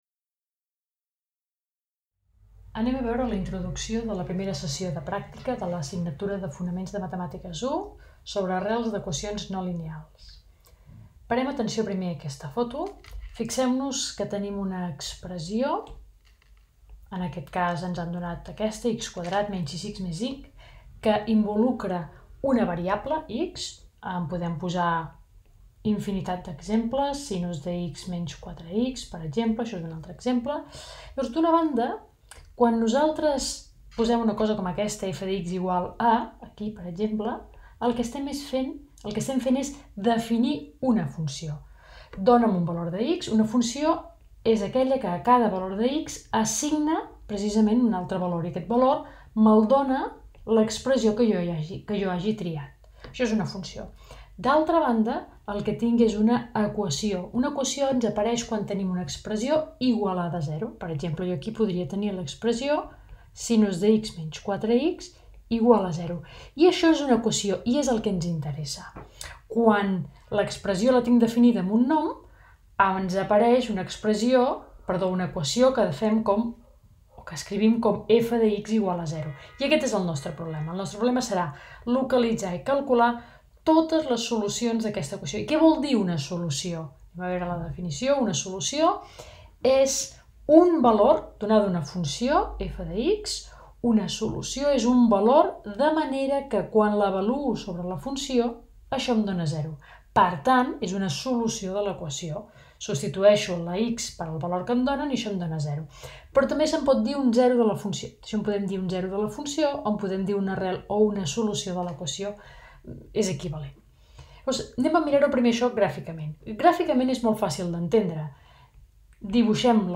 Sessió pràctica de l'assignatura de Fonaments de les matemàtiques, sobre arrels d'equacions no lineals